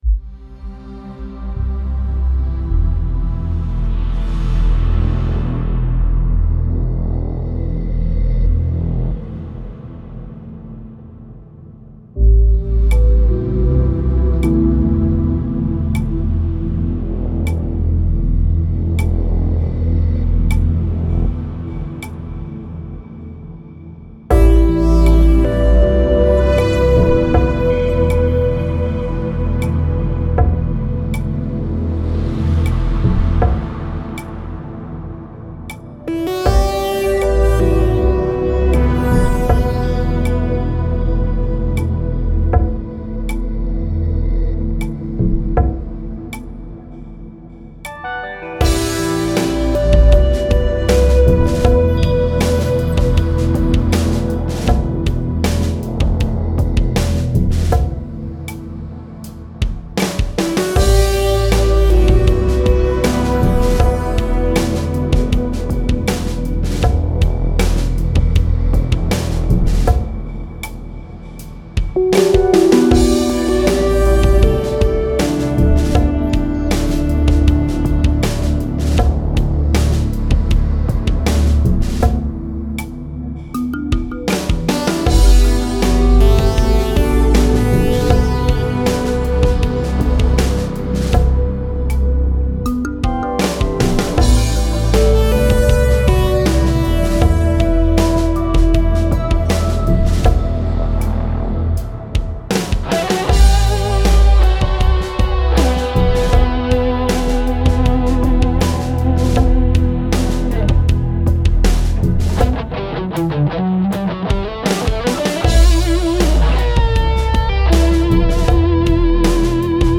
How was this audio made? a view inside the studio